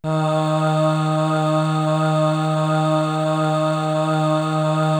Index of /90_sSampleCDs/Best Service ProSamples vol.55 - Retro Sampler [AKAI] 1CD/Partition C/CHOIR